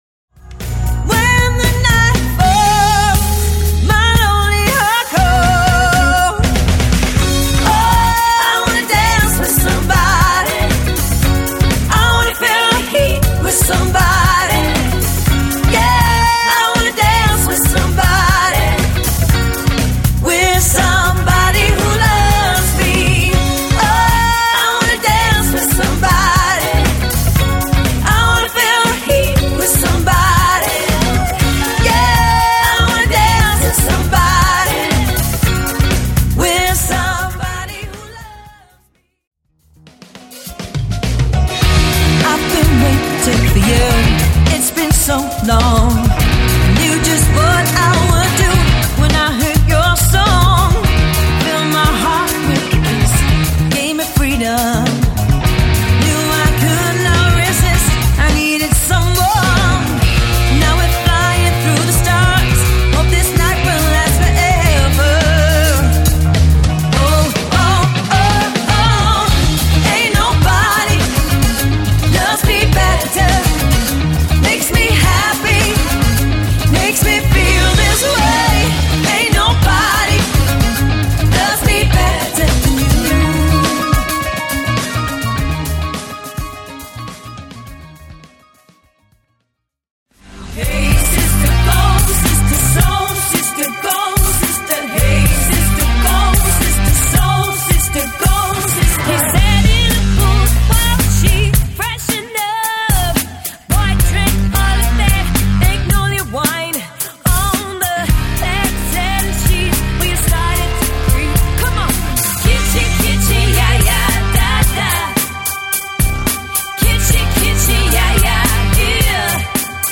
• Unique medleys with tight harmonies and choreography